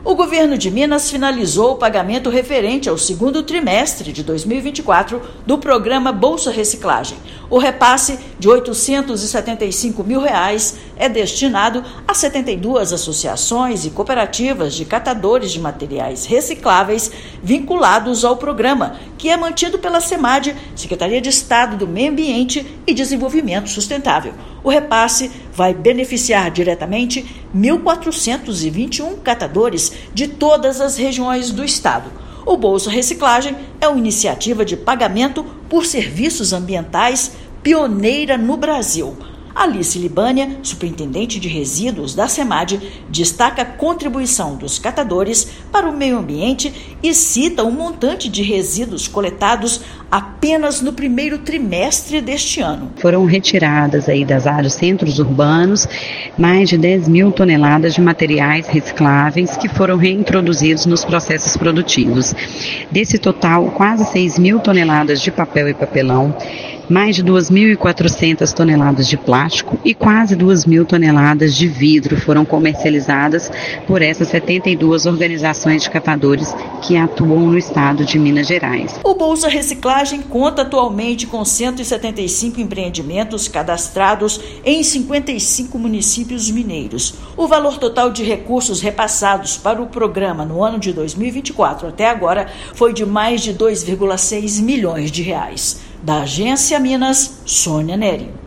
[RÁDIO] Governo de Minas realiza novo repasse do Bolsa Reciclagem a cooperativas de catadores de materiais recicláveis
Repasse de R$ 875 mil reais beneficiará 1.421 catadores de materiais recicláveis em todo o estado. Ouça matéria de rádio.